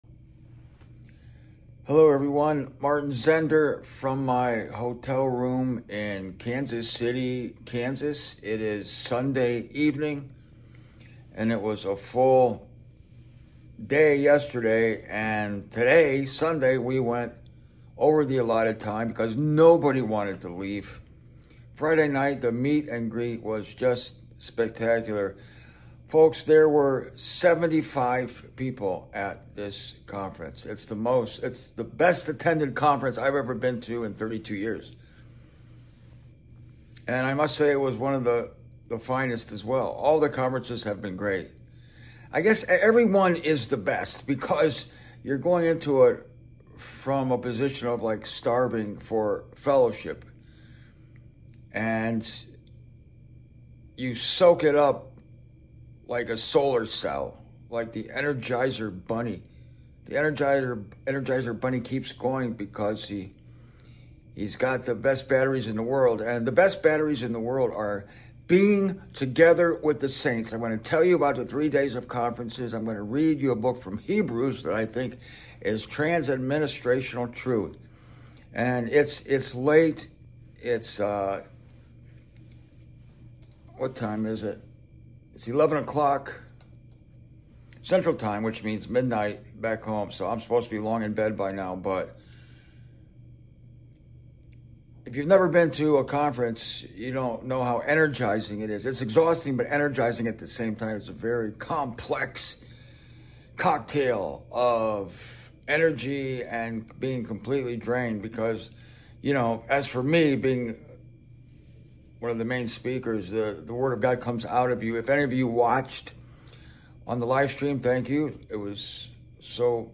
Coming to you on Sunday night from my hotel in Kansas City.